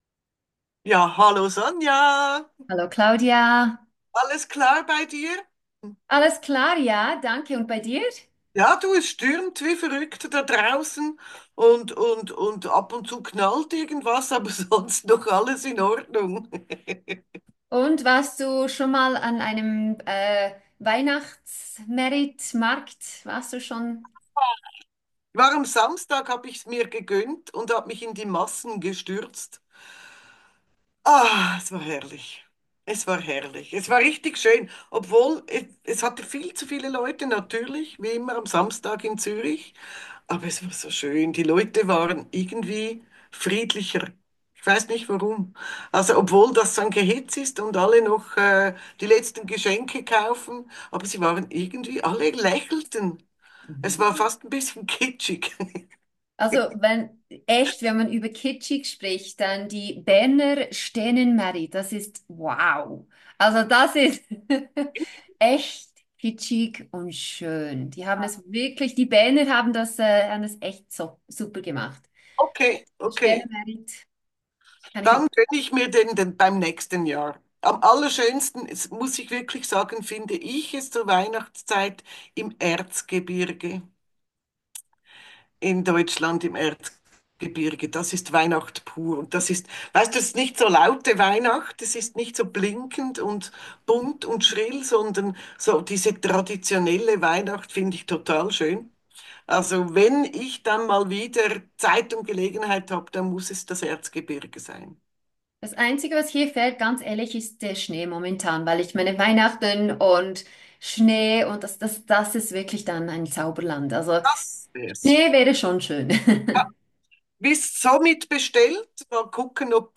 Podcast-Studio Berlin